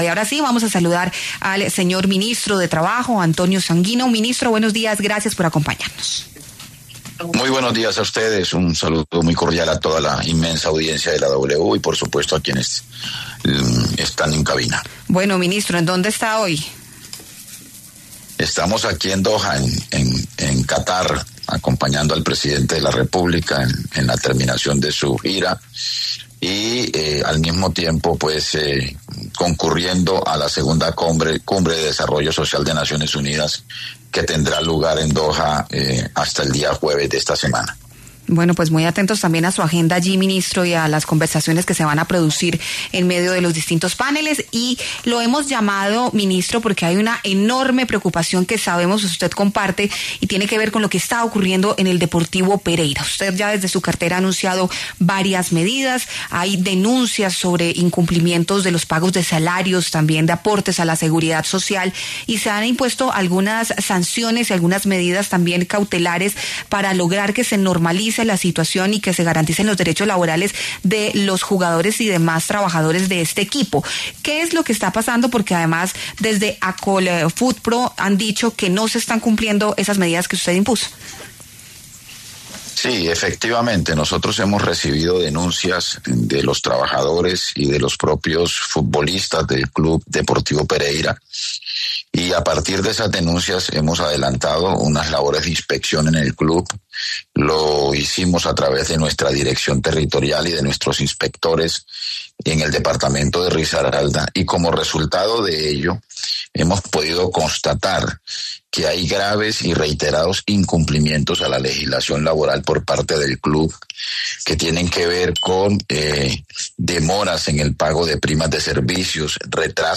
El ministro de Trabajo, Antonio Sanguino, pasó por los micrófonos de la W Radio y dio detalles del caso del Deportivo Pereira.